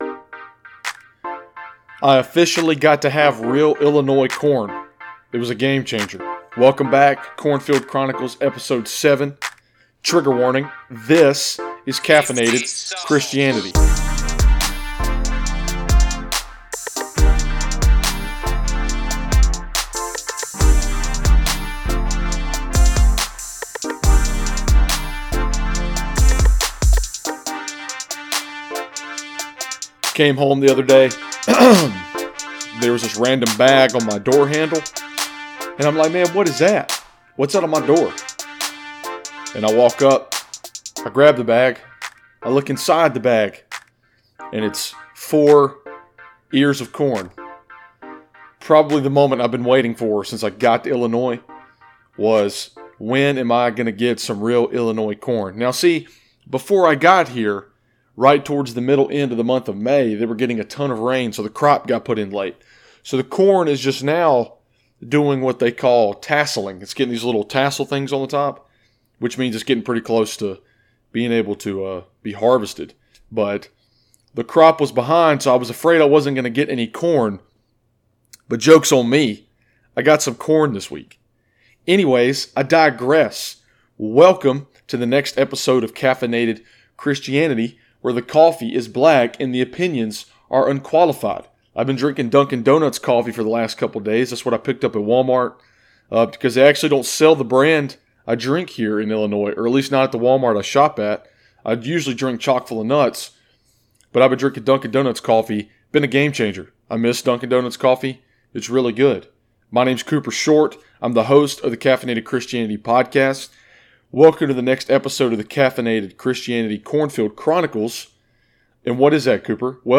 I talk about Bibles, preaching, Christian rap, crying, my little sister, and so much more. Oh, and peep the new instrumental at the end.